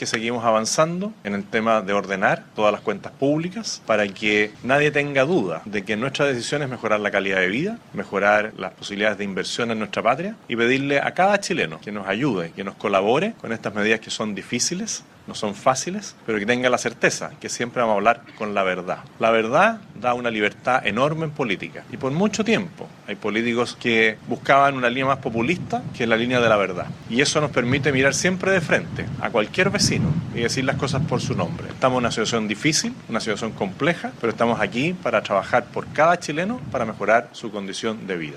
Desde la Región de La Araucanía, donde realizó una gira de dos días, el Mandatario sostuvo que el Gobierno está enfocado en ordenar las cuentas públicas.
En ese contexto, el Presidente hizo un llamado a la ciudadanía a respaldar las medidas adoptadas.